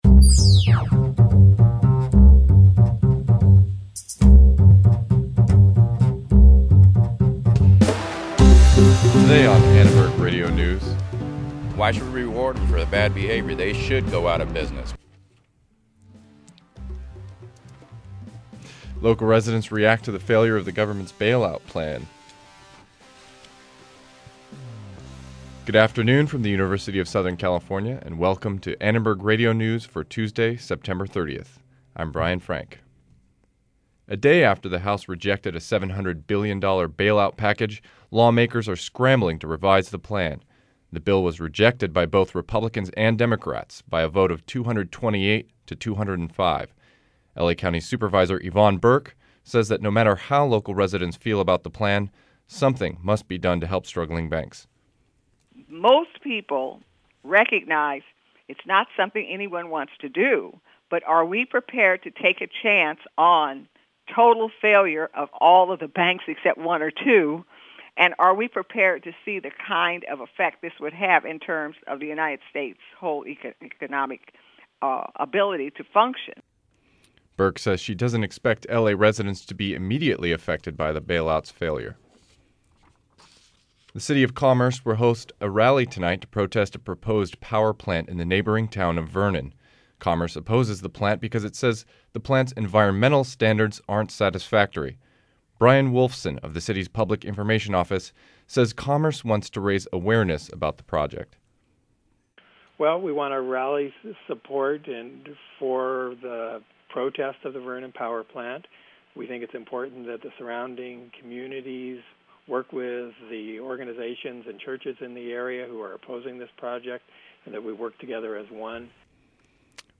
Join us as we visit a local temple to hear about what people are hoping for at the start of the Jewish New Year.